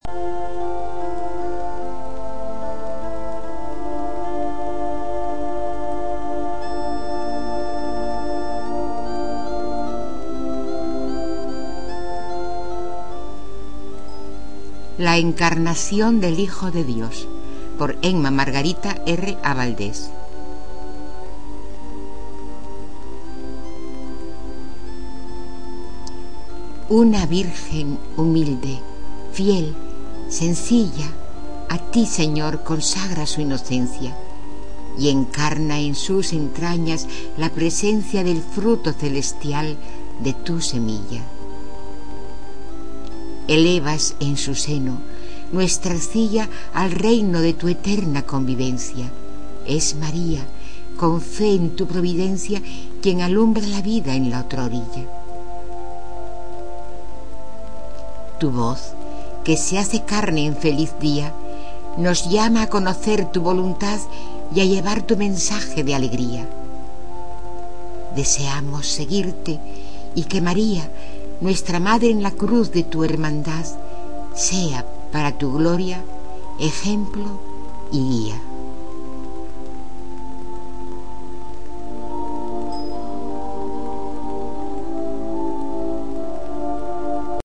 Poesías